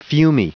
Prononciation du mot fumy en anglais (fichier audio)
Prononciation du mot : fumy